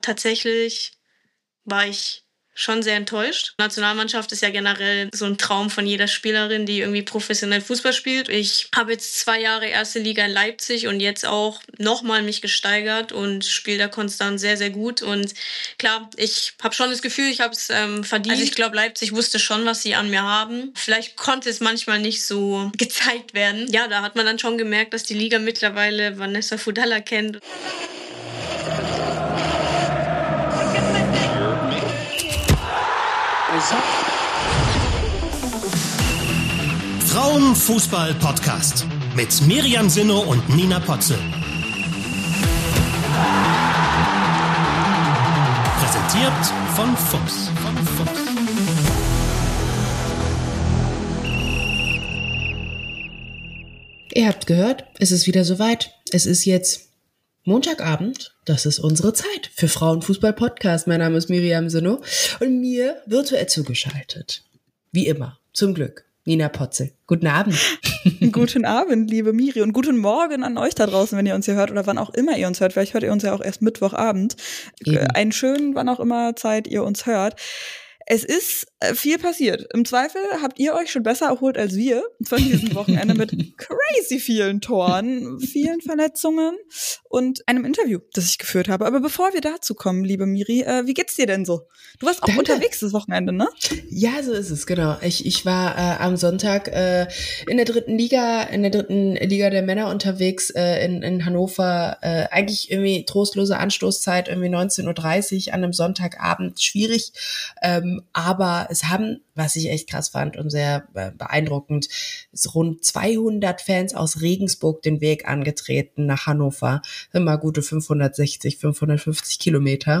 Interview ~ Frauen. Fußball. Podcast.